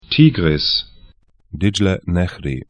Tigris 'ti:grɪs Dicle nehri 'dɪʤlɛ 'nɛçri tr Fluss / stream 37°19'N, 42°13'E